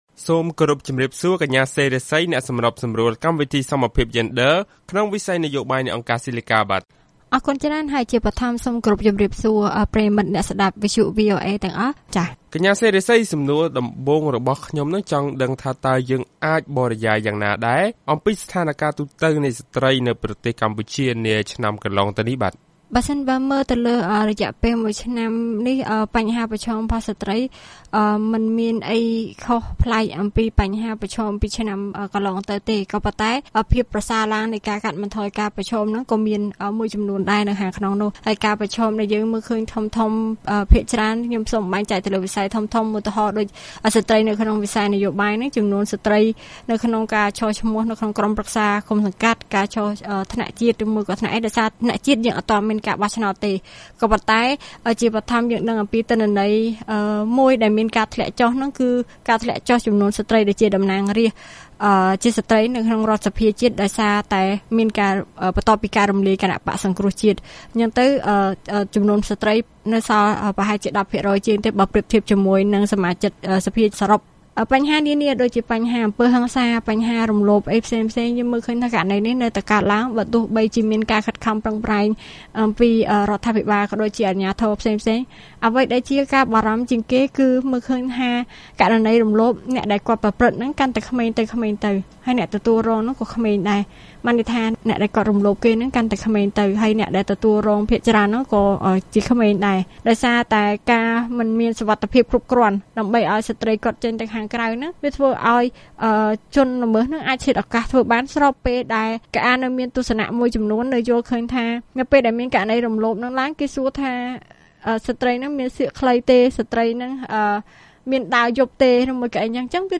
បទសម្ភាសន៍ VOA៖ អ្នកតស៊ូមតិថា ក្នុងវិបត្តិនយោបាយ ស្ត្រីជាអ្នករងគ្រោះមុនគេ